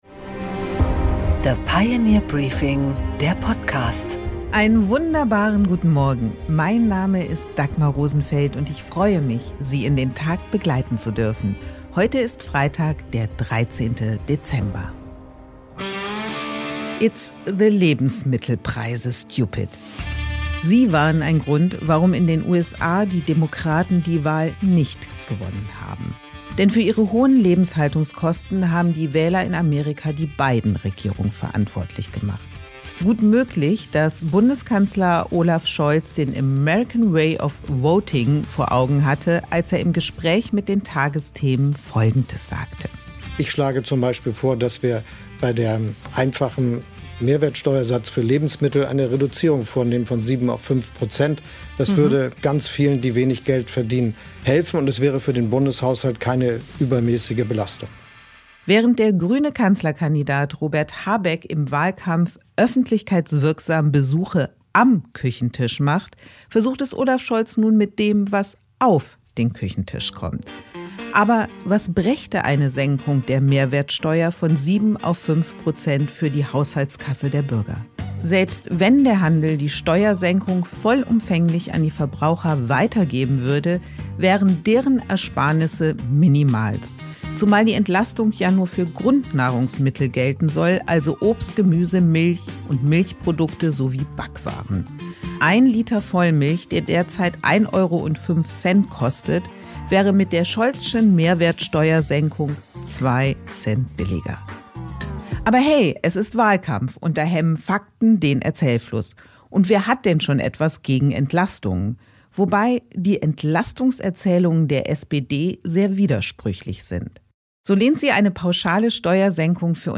Dagmar Rosenfeld präsentiert das Pioneer Briefing
Im Interview: Prof. Lars Feld und Prof. Justus Haucap besprechen in der aktuellen Folge des The Pioneer Ökonomiebriefings “Feld und Haucap” mögliche Koalitionen nach der Wahl und deren Wirtschaftspolitik.